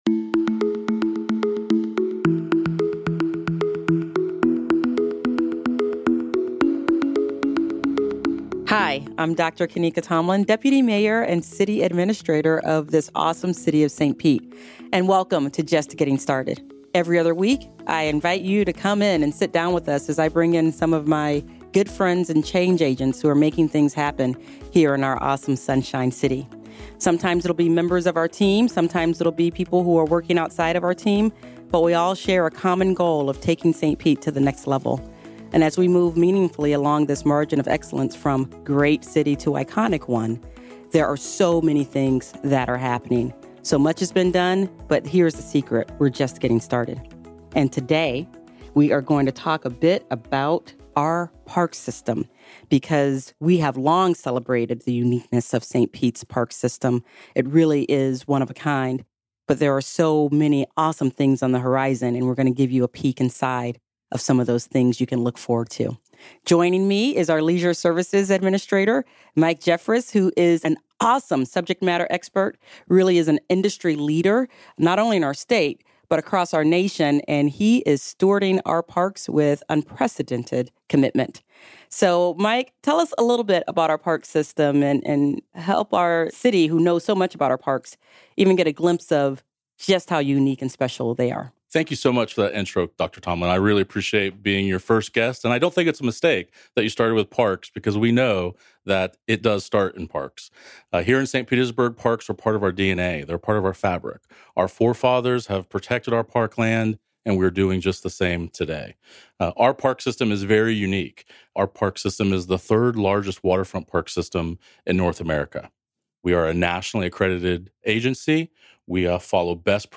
for a lively discussion of the St. Petersburg parks system.